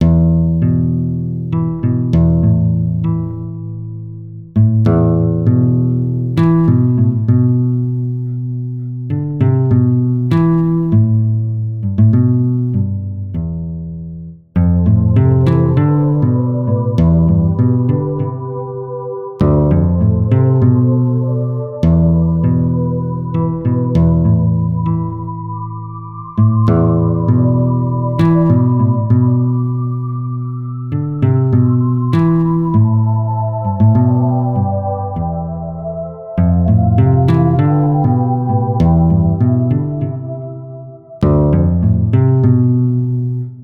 Solo de guitarra (bucle)
guitarra
melodía